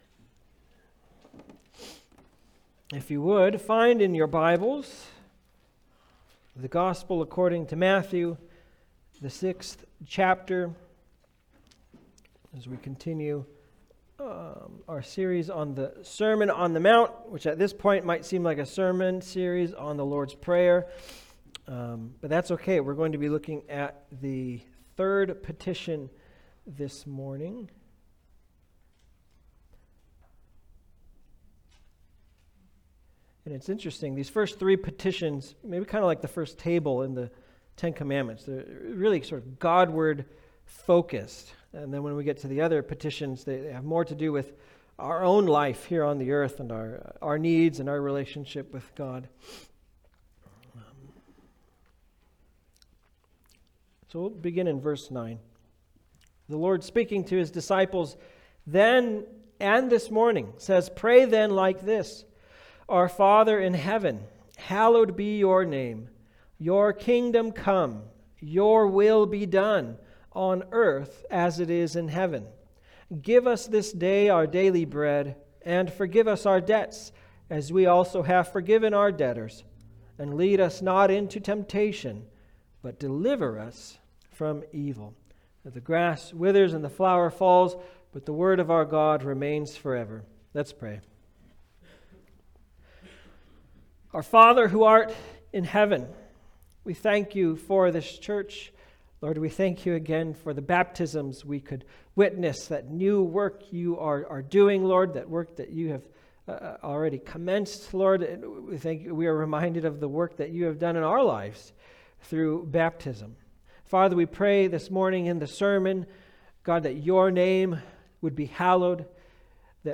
Passage: Matthew 6:10b Service Type: Sunday Service